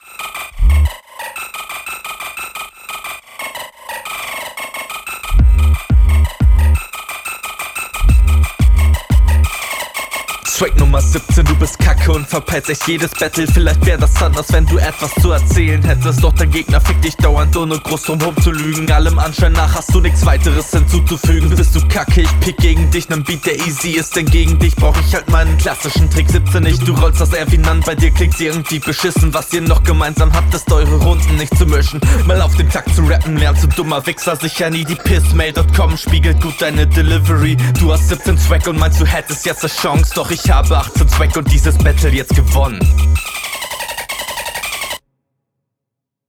Schnelles Battle Format